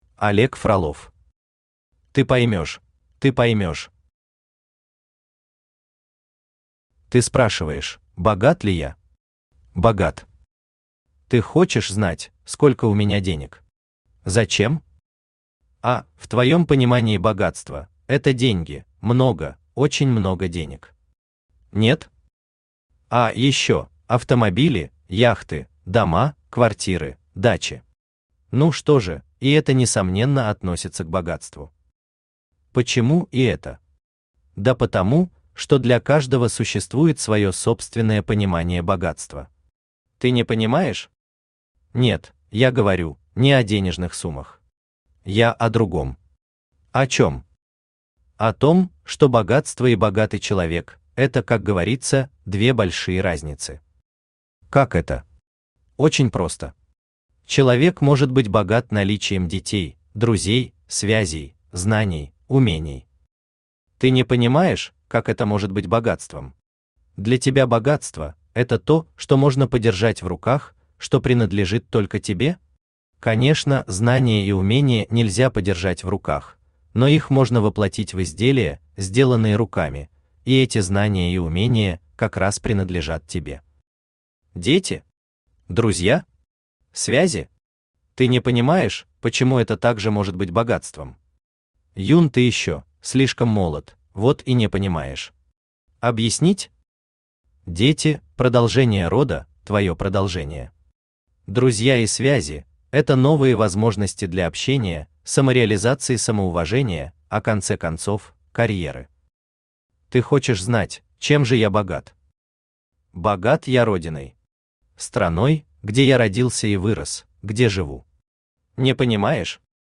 Автор Олег Васильевич Фролов Читает аудиокнигу Авточтец ЛитРес.